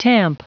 Prononciation du mot tamp en anglais (fichier audio)
Prononciation du mot : tamp